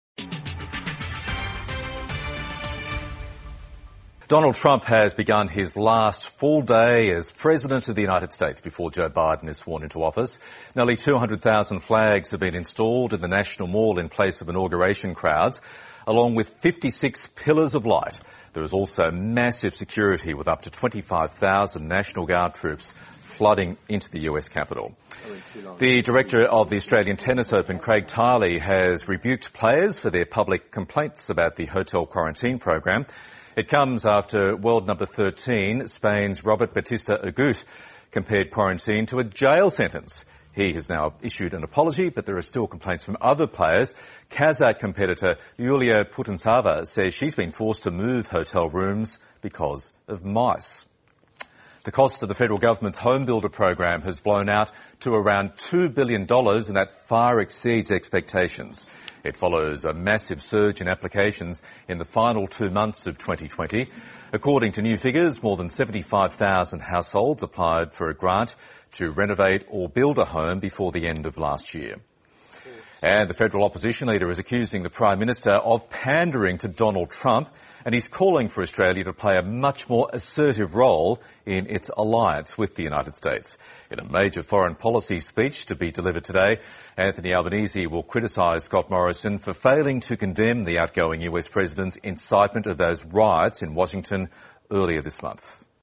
美语听力练习:特朗普离任 拜登就职新一任美国总统